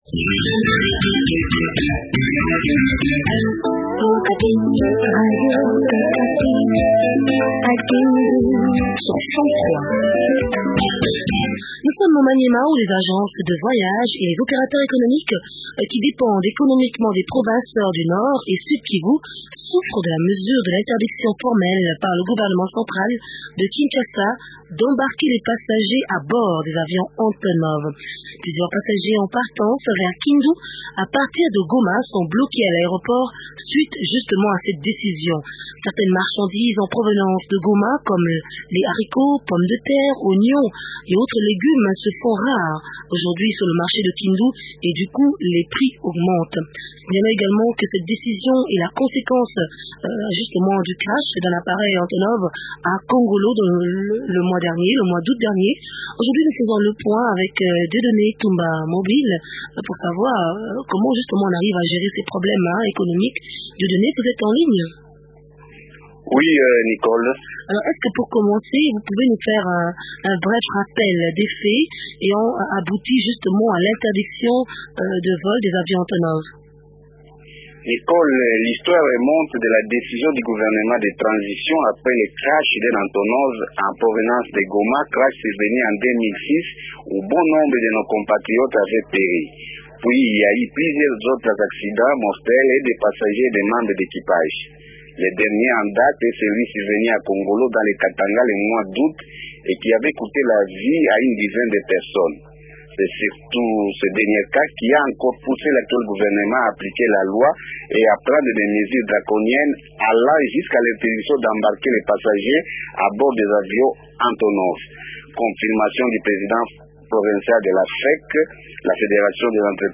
Plusieurs passagers en partance vers Kindu à partir de Goma, sont bloqués à l’aéroport suite à cette décision. Certaines marchandises en provenance de Goma comme les haricots, pommes de terre, oignons et autres légumes se font rares sur le marché de Kindu et du coût les prix augmentent. Des precisions sur la mesure gouvernementale dans cet entretien avec Florent Tambwe Lukunda, ministre provincial des infrastructures trava